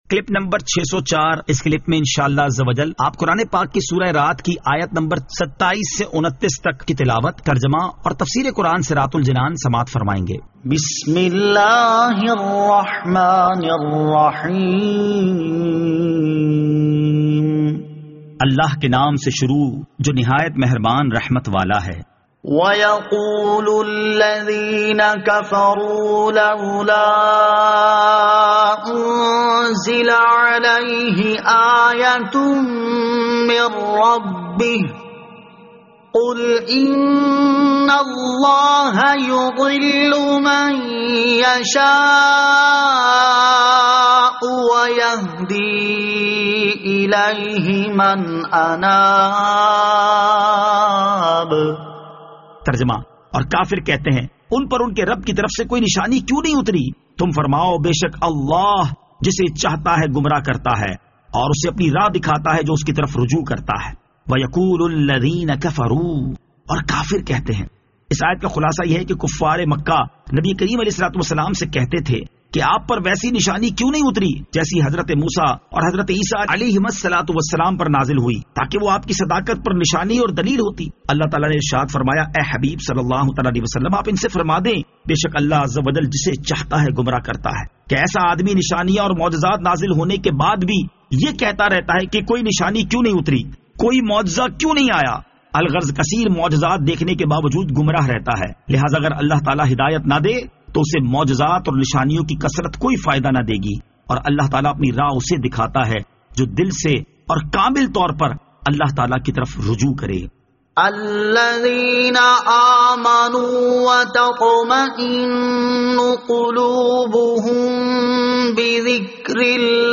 Surah Ar-Rad Ayat 27 To 29 Tilawat , Tarjama , Tafseer